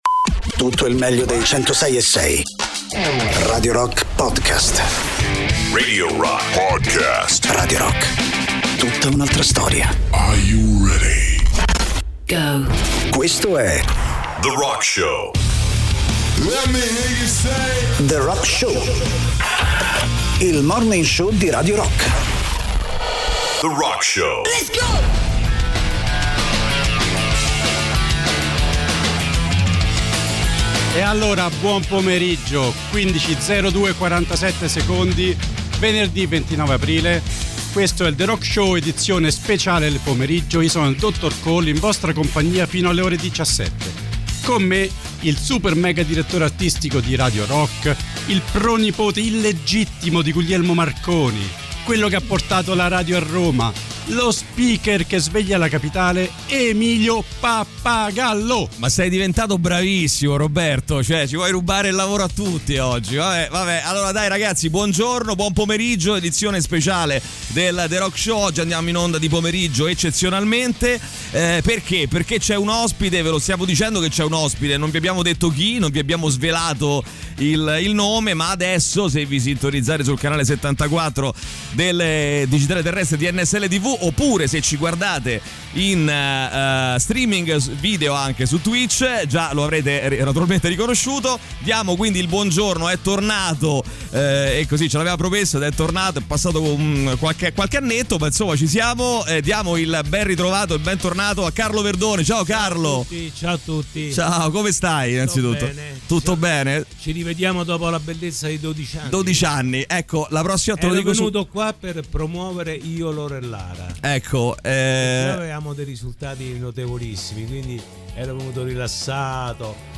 Interviste: Carlo Verdone (29-04-22)